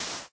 sand1.ogg